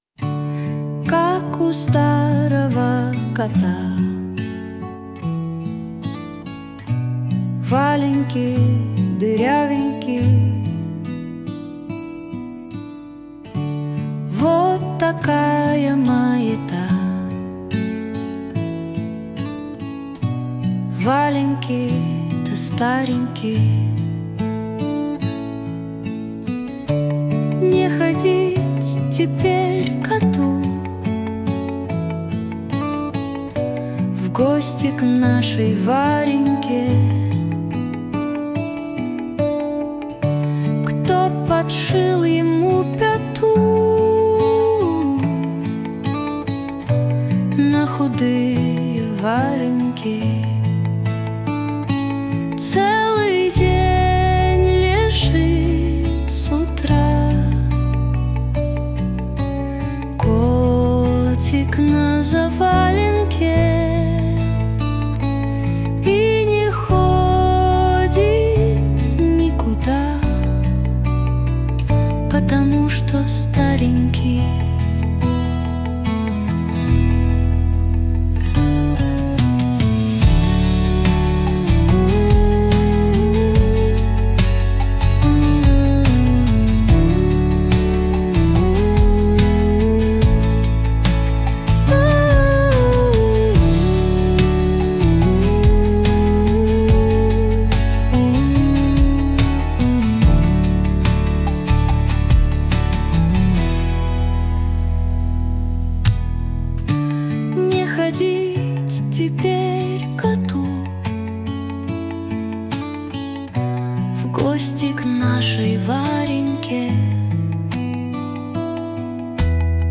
• Аранжировка: Ai
• Жанр: Детская